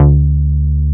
R MOOG D3MF.wav